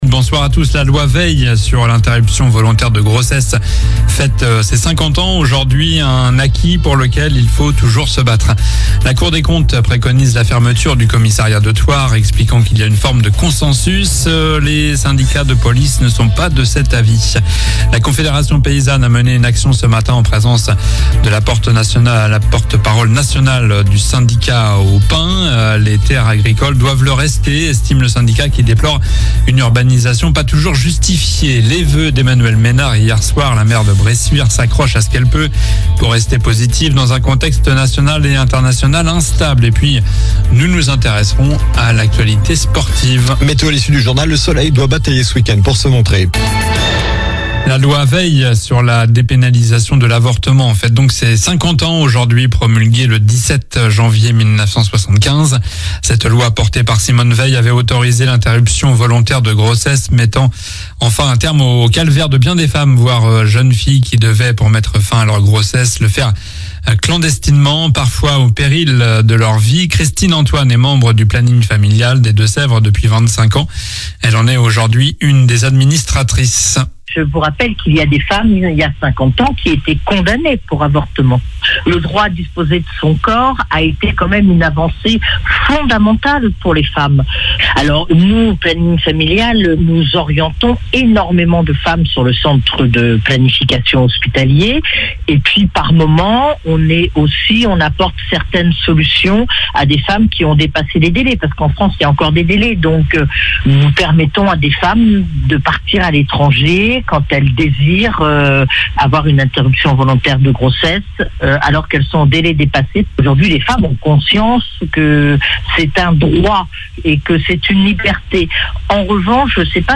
Journal du vendredi 17 janvier (soir)